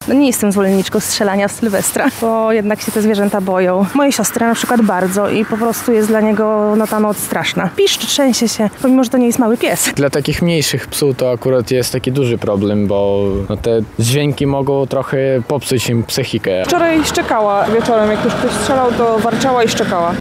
Zapytaliśmy mieszkańców Lublina, jak ich pupile reagują na huk petard.